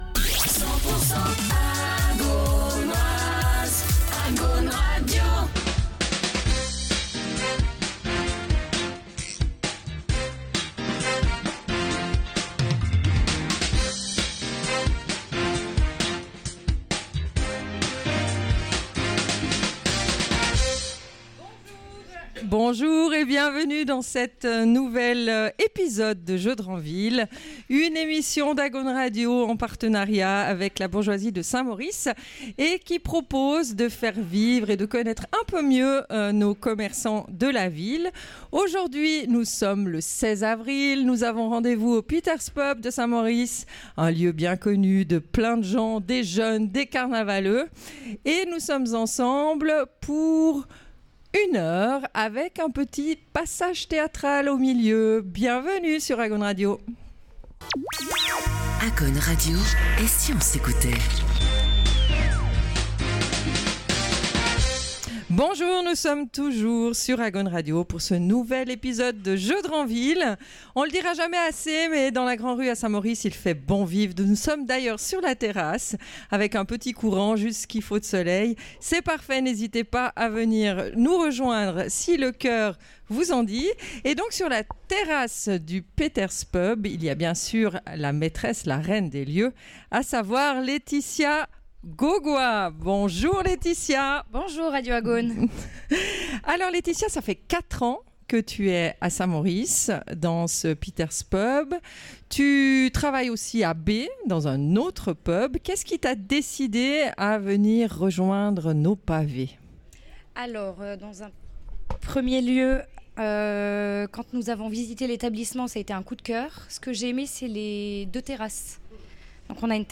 En direct du Peter's Pub à Saint-Maurice